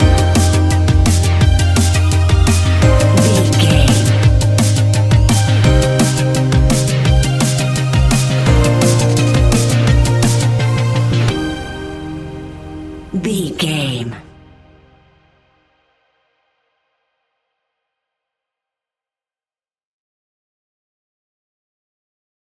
Ionian/Major
C♭
techno
trance
synths
synthwave
instrumentals